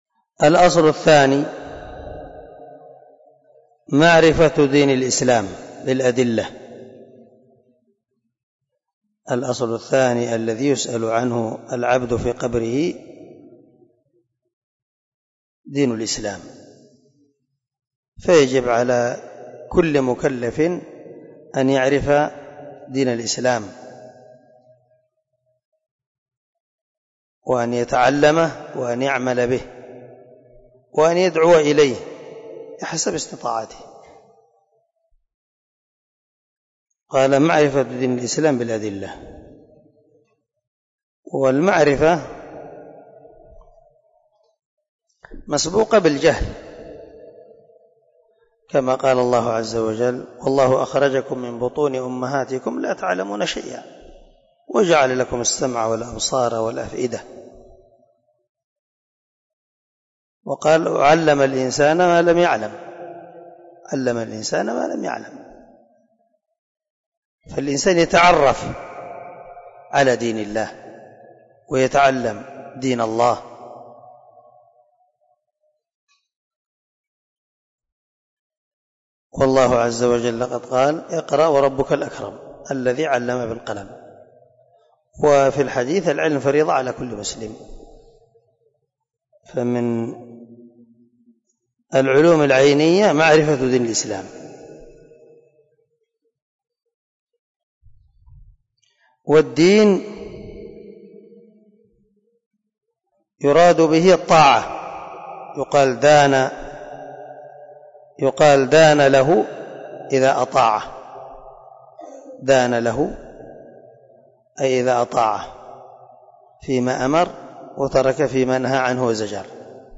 🔊 الدرس 22 من شرح الأصول الثلاثة
الدرس-22-الأصل-الثاني.mp3